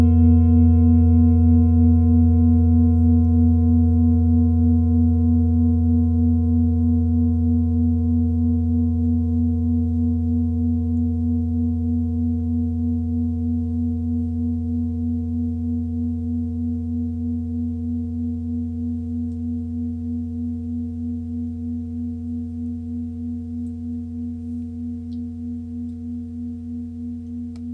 Klangschalen-Gewicht: 1500g
Klangschalen-Durchmesser: 26,3cm
Der Klang einer Klangschale besteht aus mehreren Teiltönen.
Die Klangschale hat bei 85.44 Hz einen Teilton mit einer
Die Klangschale hat bei 240.96 Hz einen Teilton mit einer
klangschale-nepal-46.wav